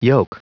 Prononciation du mot yolk en anglais (fichier audio)
Prononciation du mot : yolk